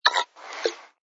sfx_slurp_bottle01.wav